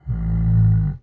食人魔" 呻吟3
描述：食人魔（或其他大型怪物）呻吟的声音
Tag: 呻吟 呻吟 怪物